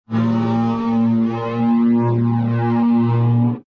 Descarga de Sonidos mp3 Gratis: llamado animal 1.